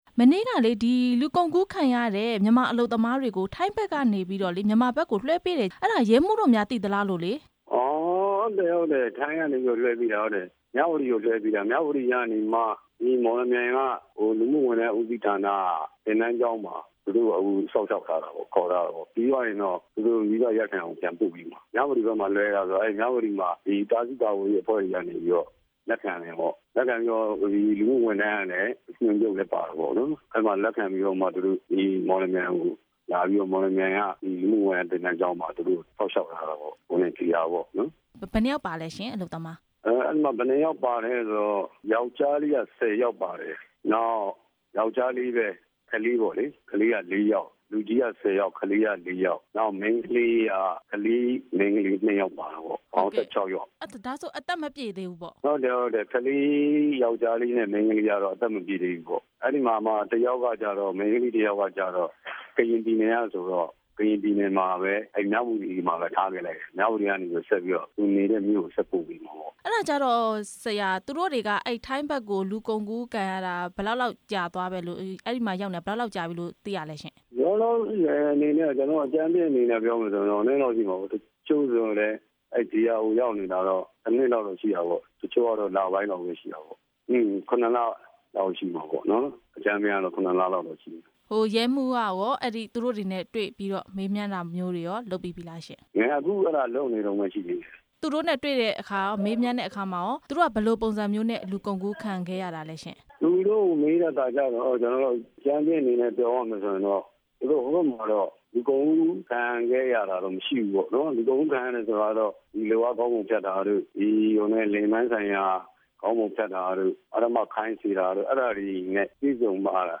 လူကုန်ကူးခံရသူလို့ ယူဆသူ ၁၆ ဦးနဲ့ ပတ်သက်ပြီး မေးမြန်းချက်